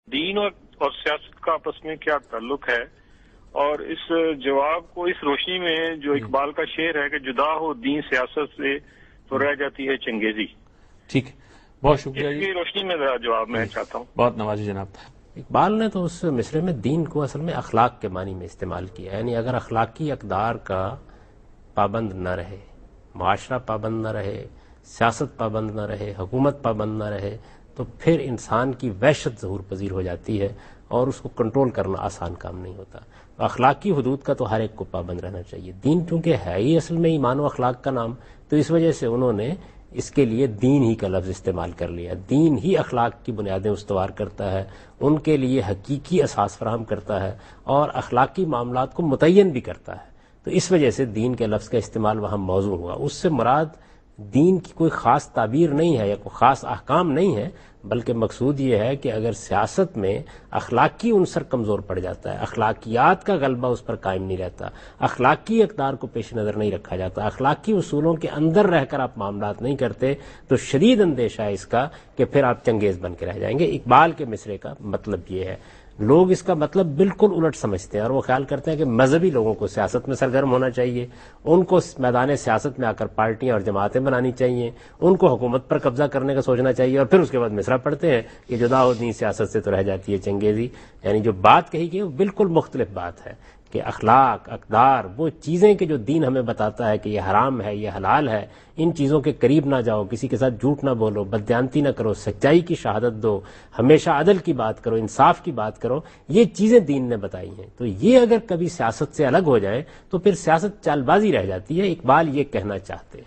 Javed Ahmad Ghamidi answers a question regarding"Religion and Politics" in program Deen o Daashi on Dunya News.
جاوید احمد غامدی دنیا نیوز کے پروگرام دین و دانش میں مذہب اور سیاست سے متعلق ایک سوال کا جواب دے رہے ہیں۔